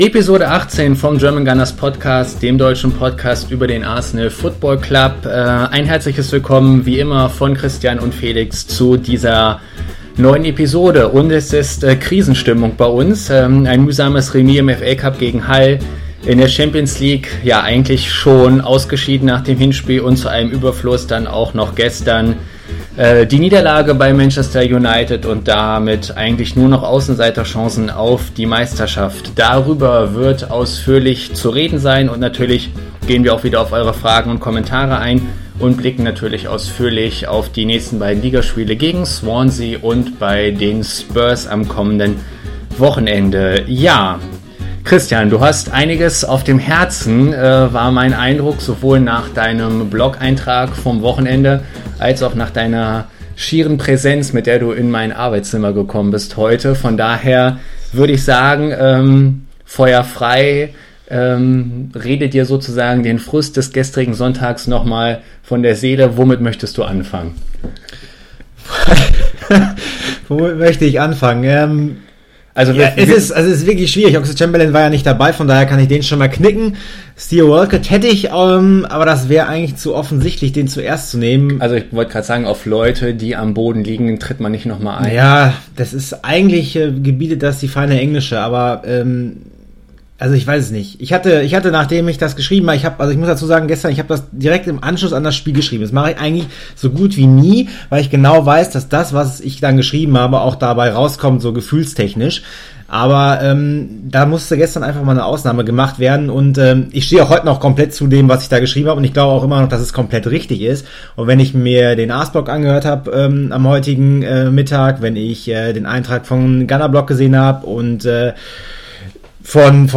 Es wird und ist emotional. Einem Krisenpodcast würdig eben. Natürlich blicken wir auch auf die kommenden Spiele und das Thema „Meisterschaft“.